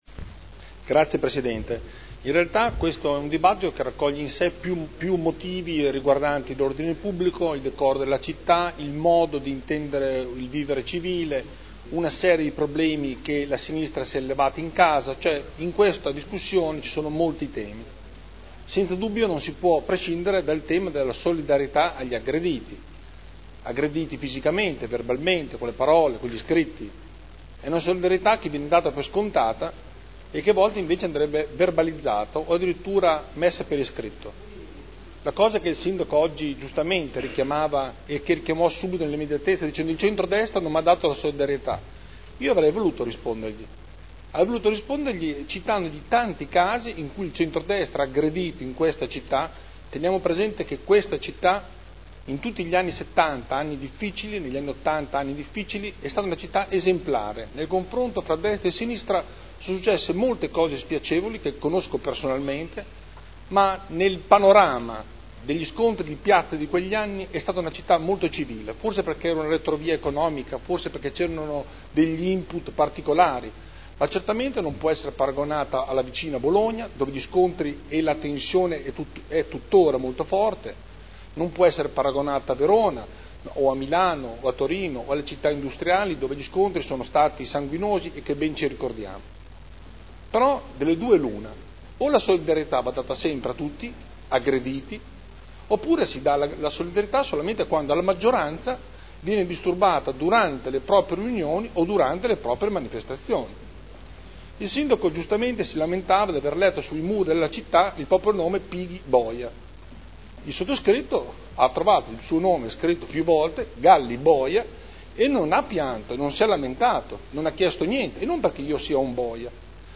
Seduta del 30/05/2011.